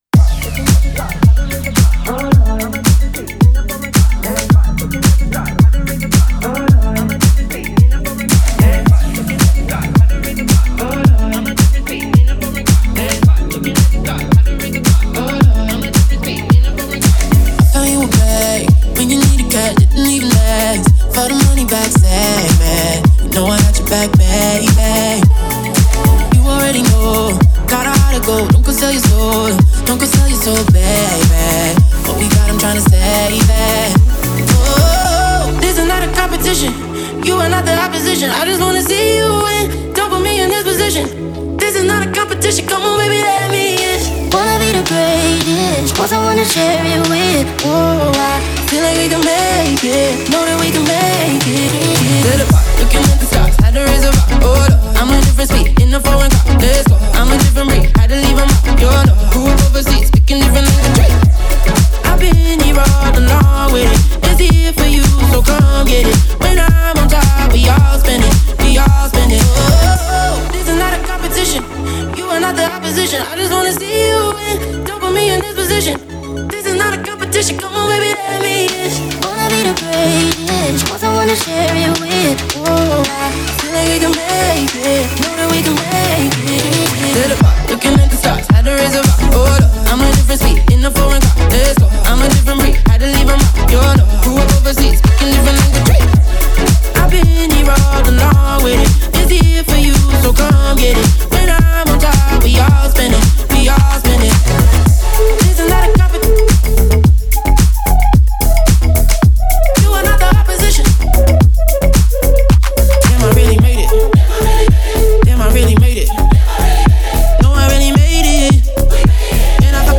мощного вокала